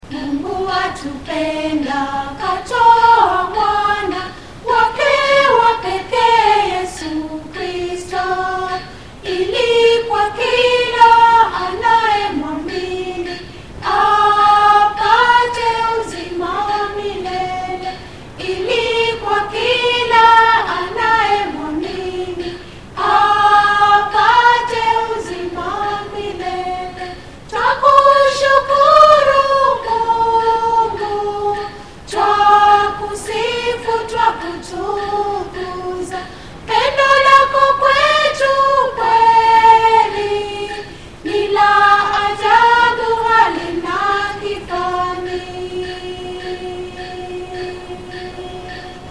Key F#